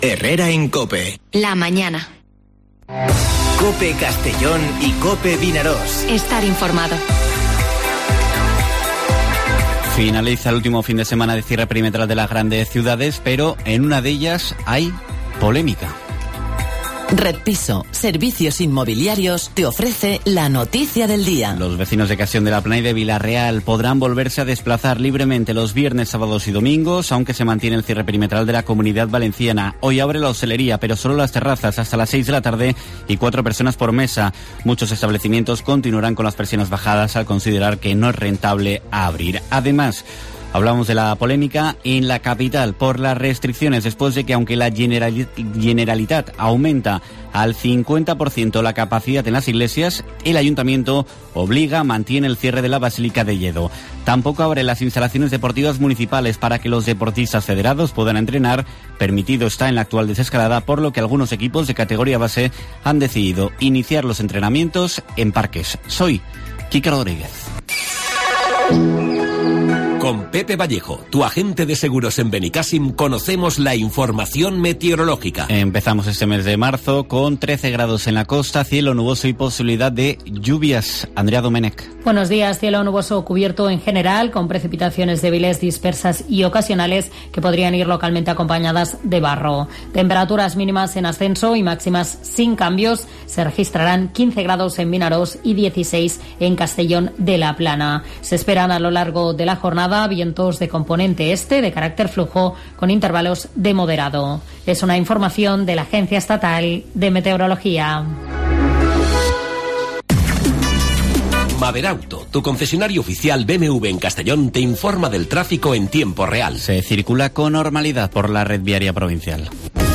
Informativo Herrera en COPE en la provincia de Castellón (01/03/2021)